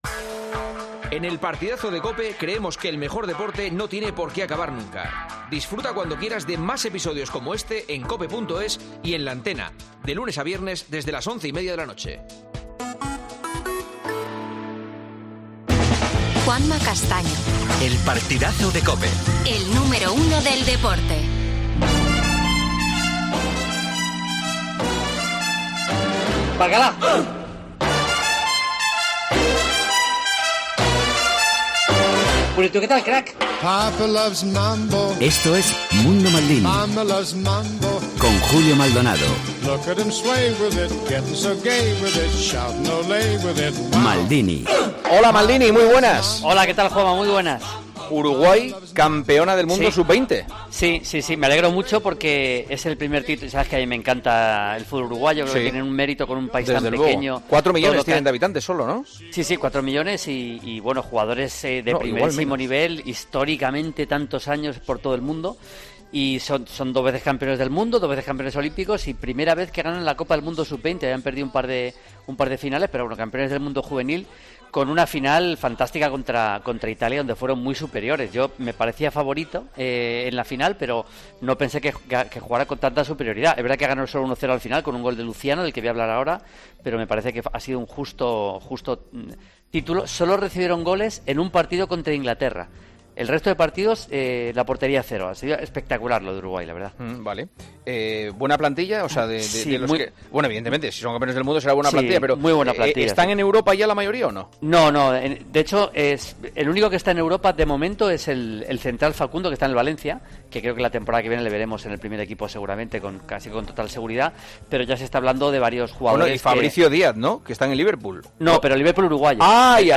Once más destacado de la Champions League. Preguntas de los oyentes.